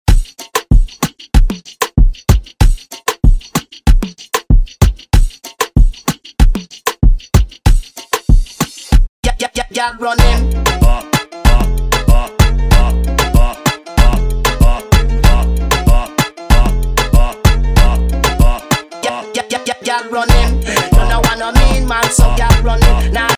also creates exotic remixes across all Latin genres.
DJ